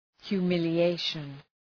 Προφορά
{hju:,mılı’eıʃən}